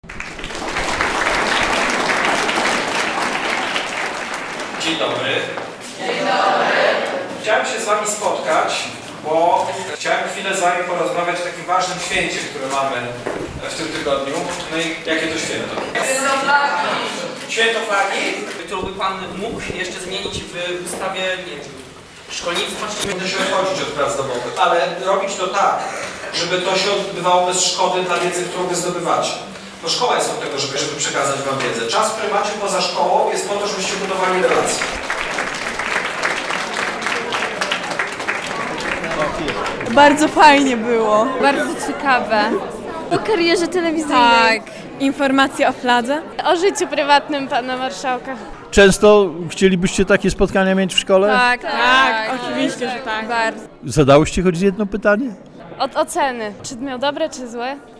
Lekcja obywatelska przeprowadzona przez marszałka